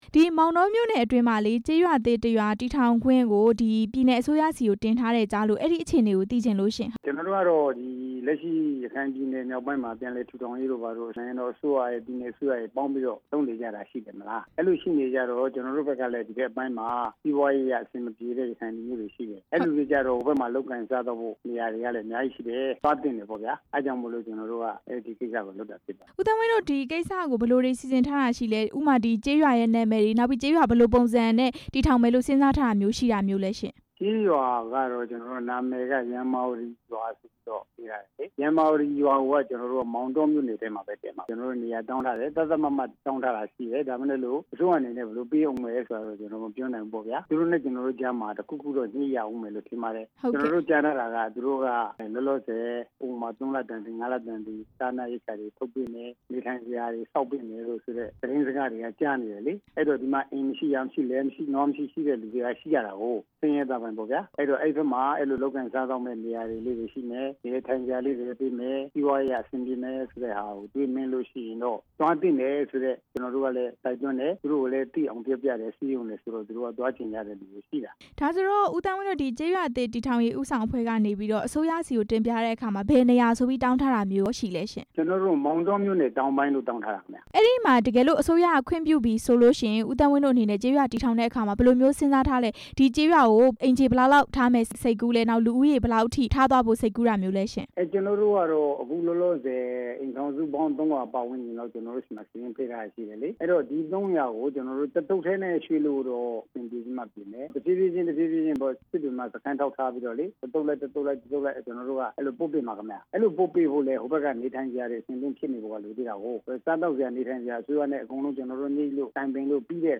ရမ္မာဝတီ ရွာသစ် တည်ထောင်မယ့်အကြောင်း မေးမြန်းချက်